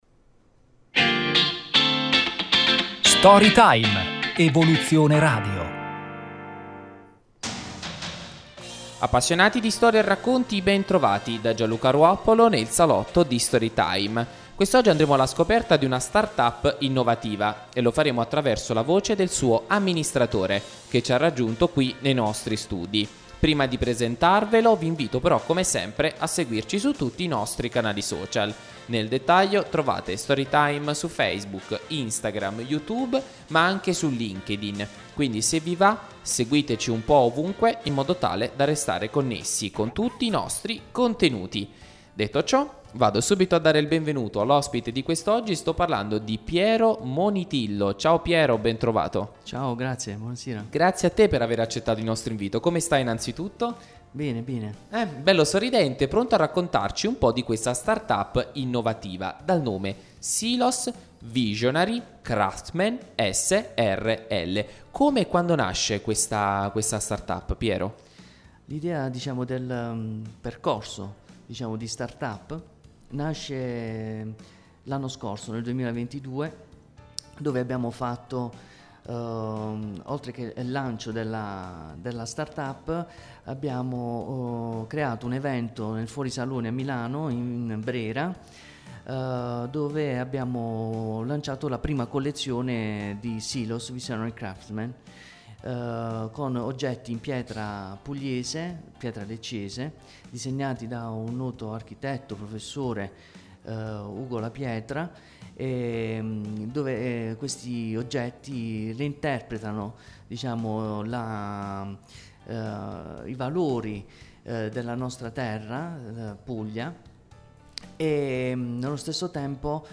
SCARICA QUI IL PODCAST DELL'INTERVISTA